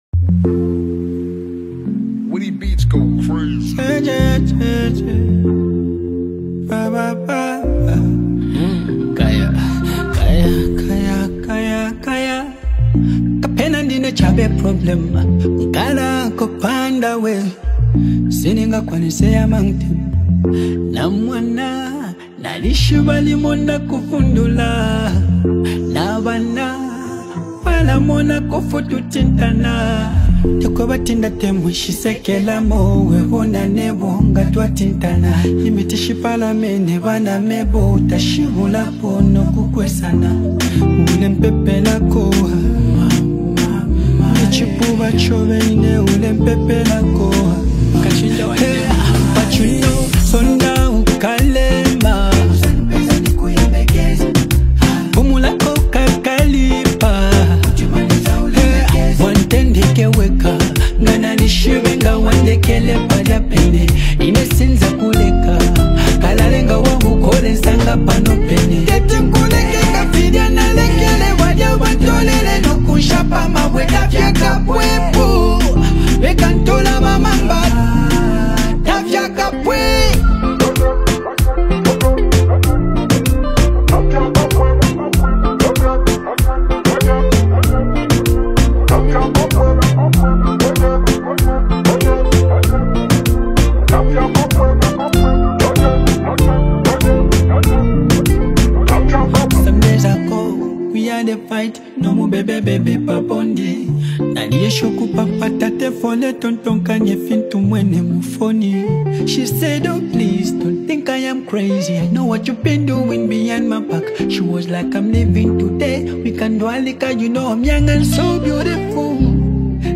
is a powerful and uplifting song